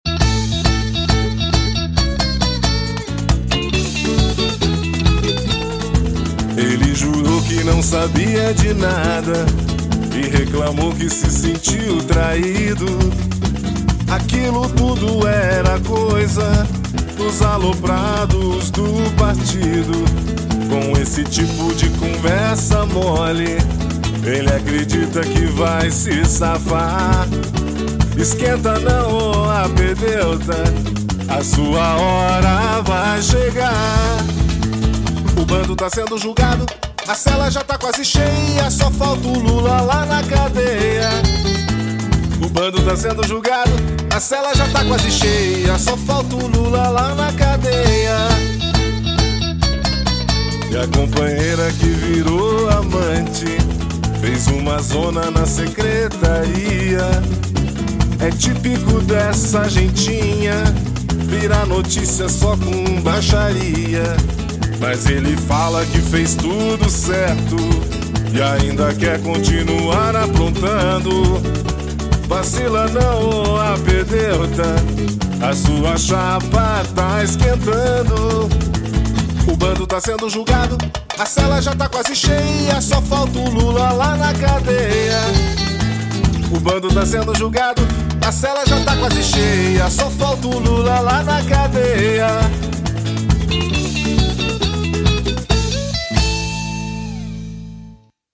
Marchinha de Carnaval 2014; Lula-lá na Cadeia